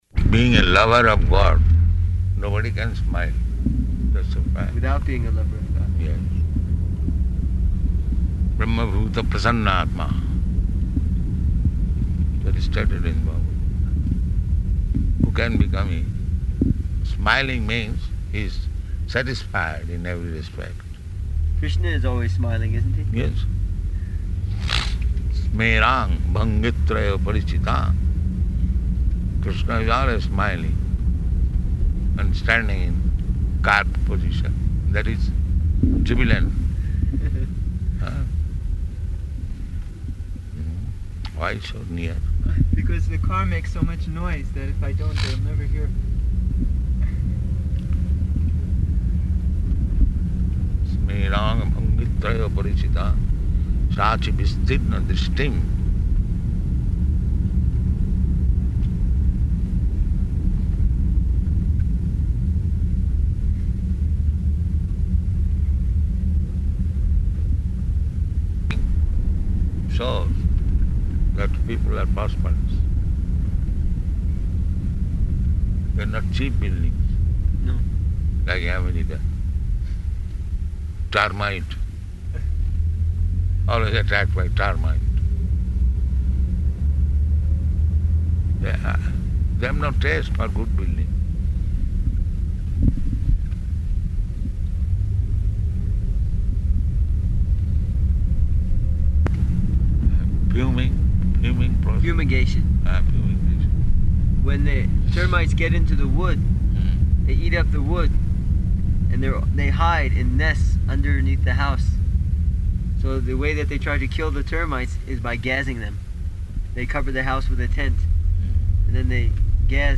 Morning Walk --:-- --:-- Type: Walk Dated: October 17th 1975 Location: Johannesburg Audio file: 751017MW.JOH.mp3 [in car] Prabhupāda: ...being a lover of God, nobody can smile, that's a fact.
Because the car makes so much noise that if I don't, they'll never hear.